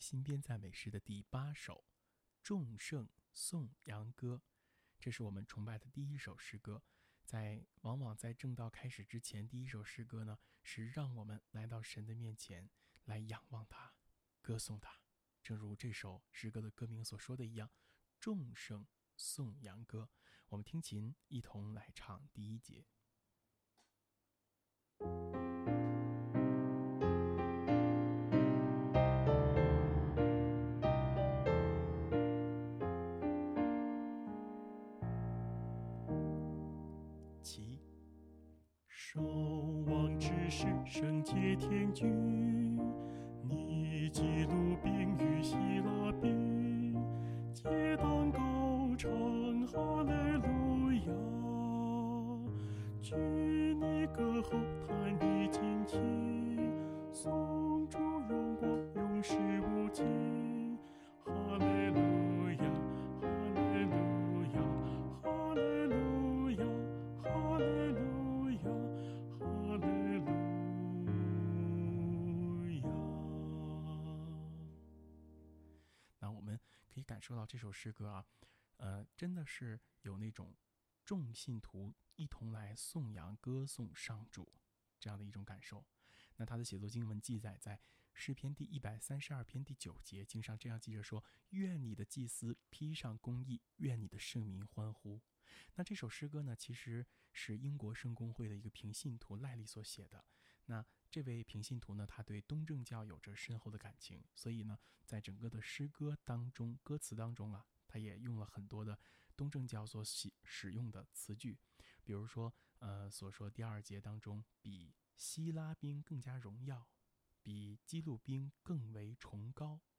【颂唱练习】